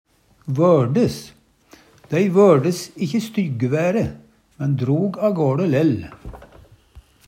vørdes - Numedalsmål (en-US)